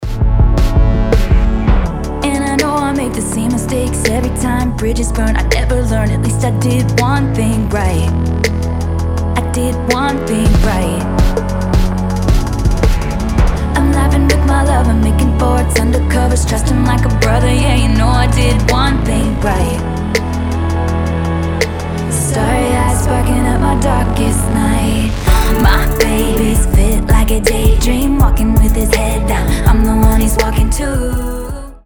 • Качество: 320, Stereo
поп
красивые
Electronic
спокойные
Bass
нежные